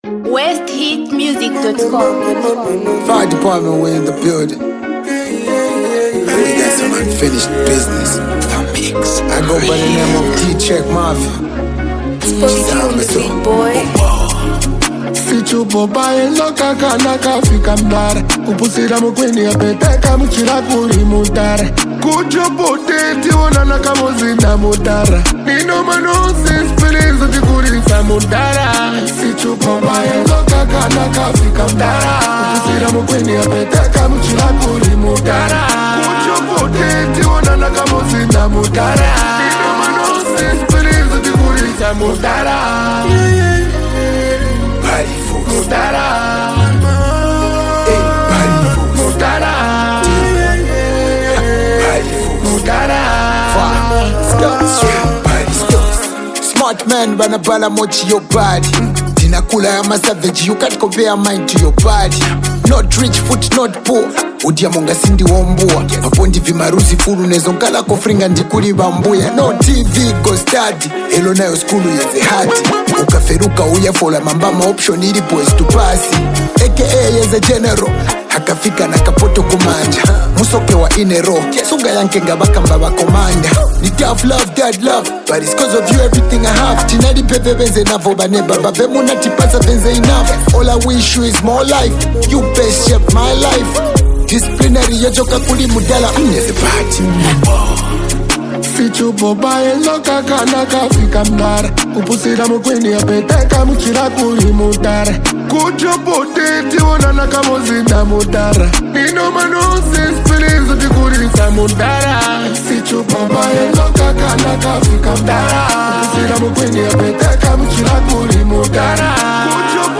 Zambian hip-hop